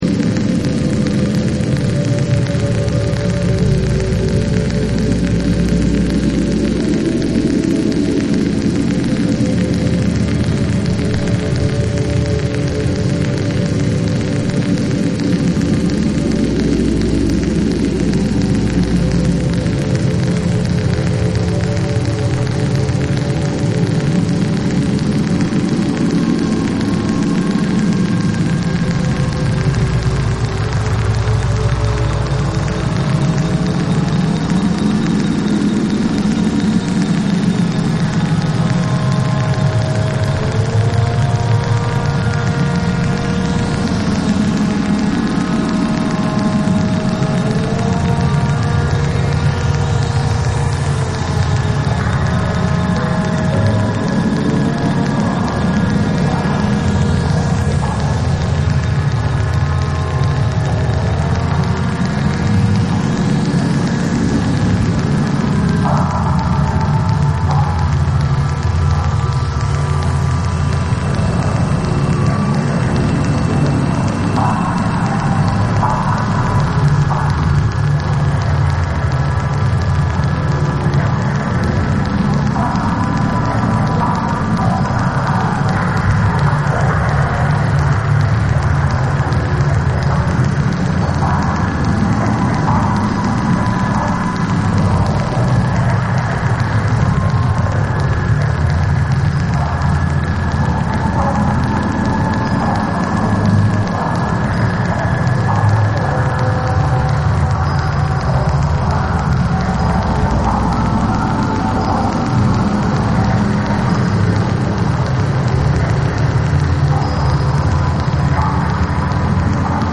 暗黒でドープな世界を連想しそうなノンビート・トラックの2。
TECHNO & HOUSE